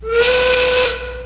pfiff.mp3